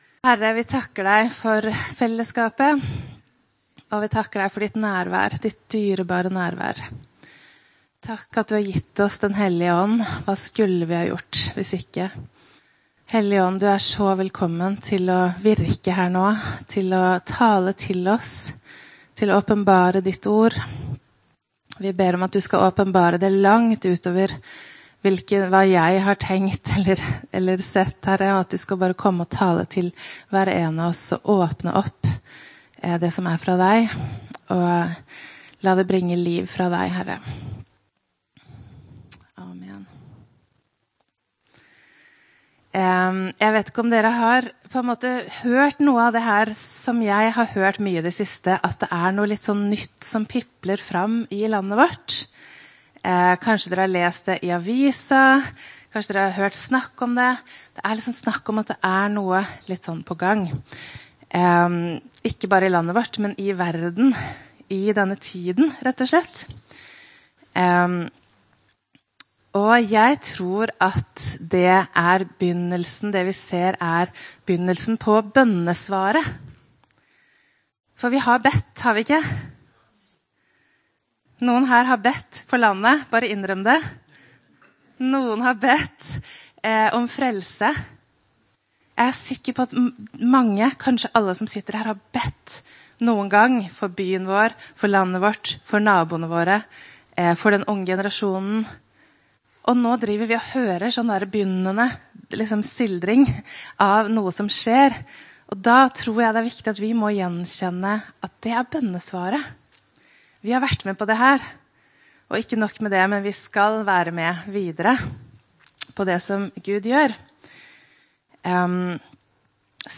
Opptak av tale